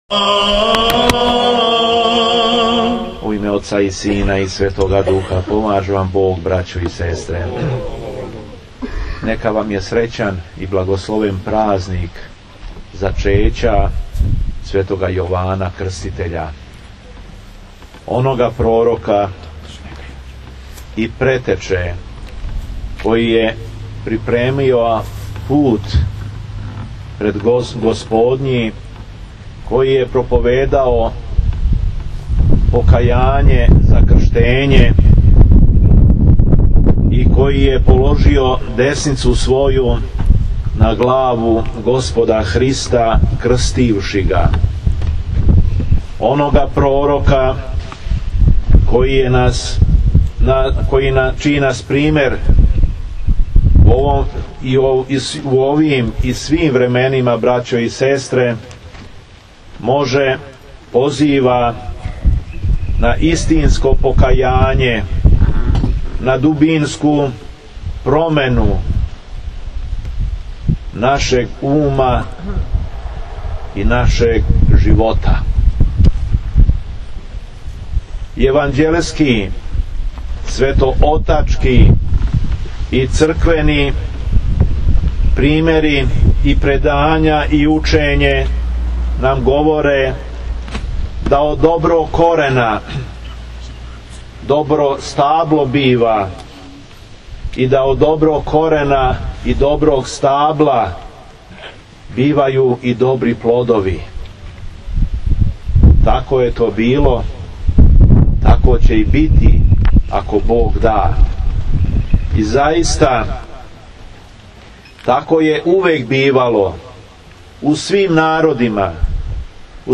Беседа епископа шумадијског Г. Јована у Поскурицама код Крагујевца